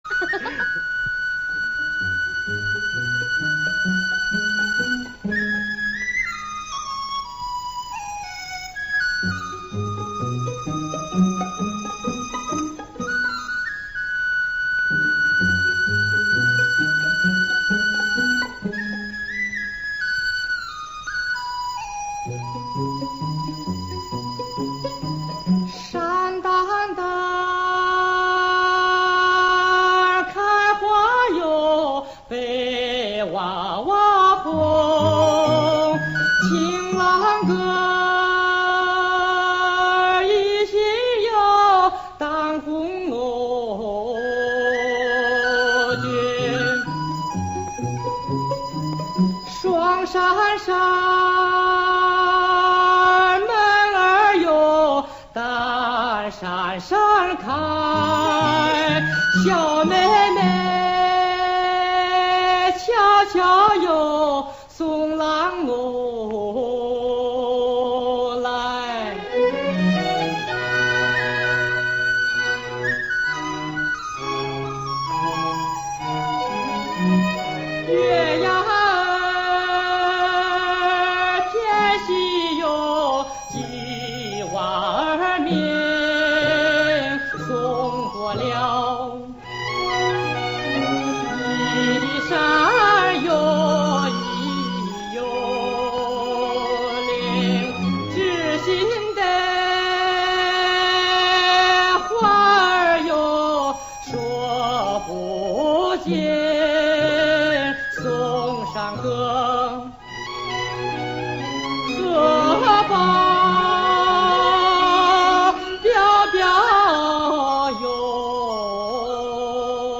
情歌